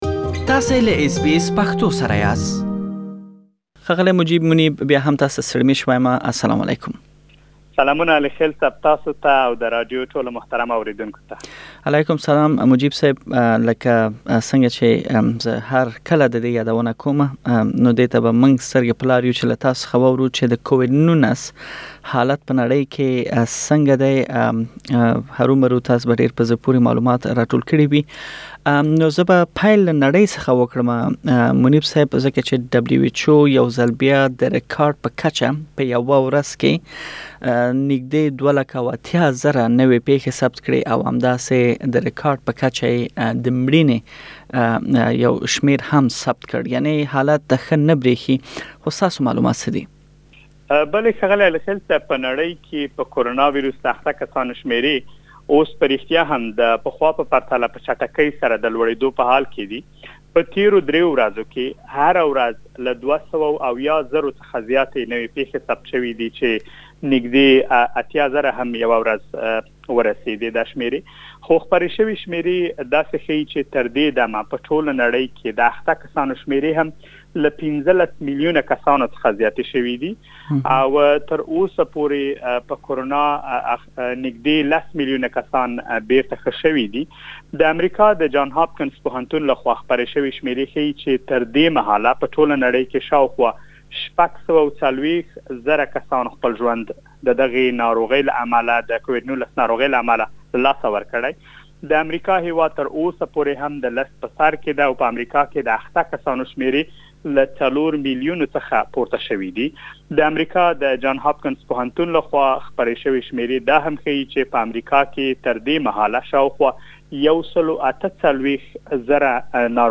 د نن ورځې د رپوټ مهم ټکي